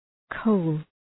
Προφορά
{kəʋl}